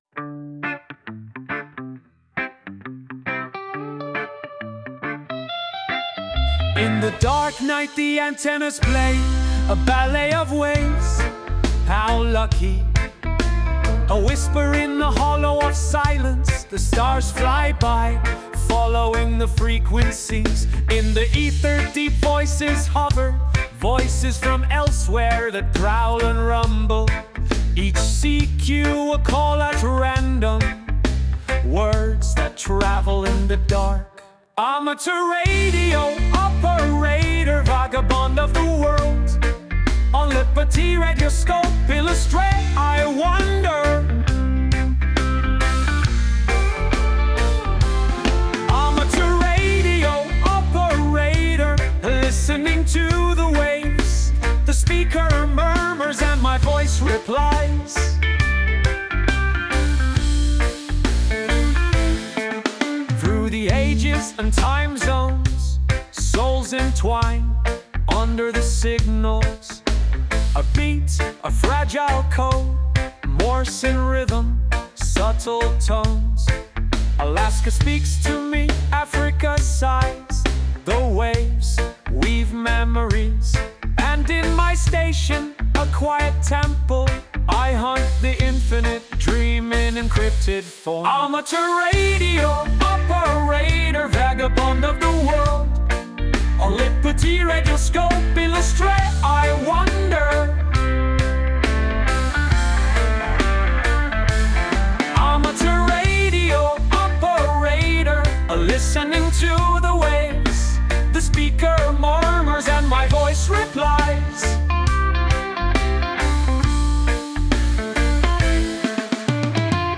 Английская версия
С помощью ИИ я выбрал немного иную музыкальную аранжировку.